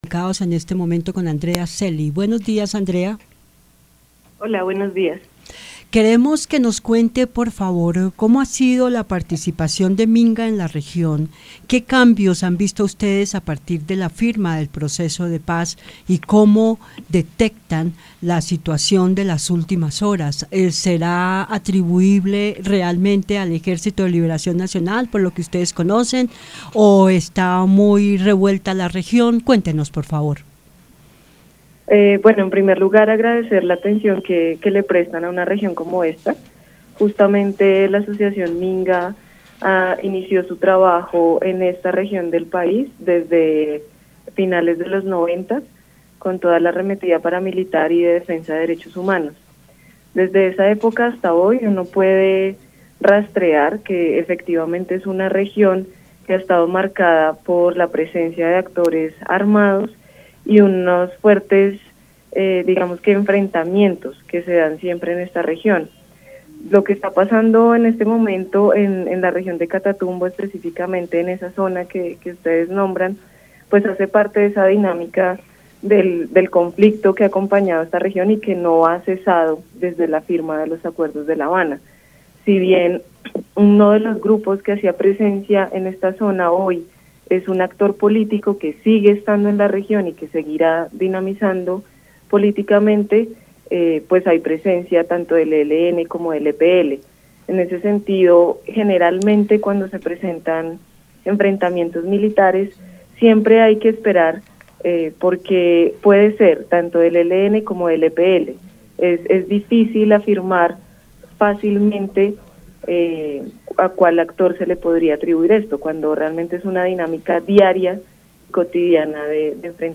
Para acercarnos al detalle sobre cómo vive la región, Aquí y Ahora conversó con representantes de la Asociación Minga, presente en la zona de conflicto, desde la década de los años 90.